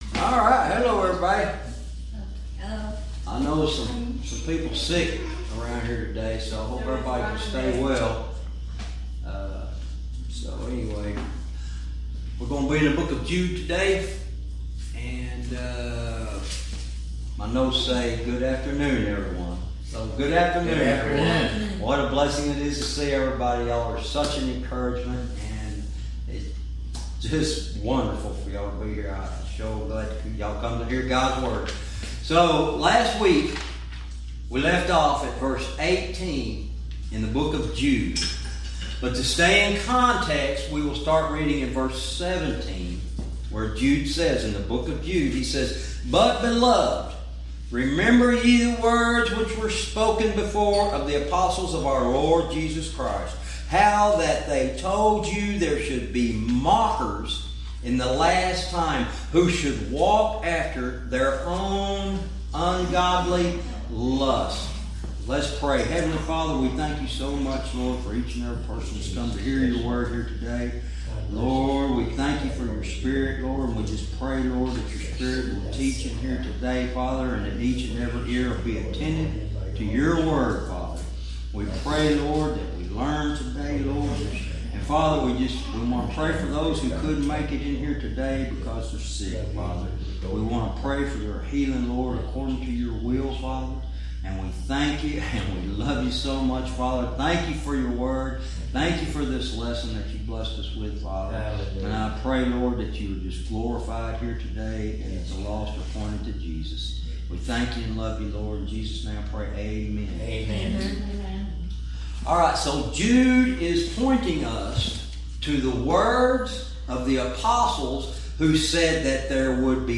Verse by verse teaching - Jude lesson 78 verse 18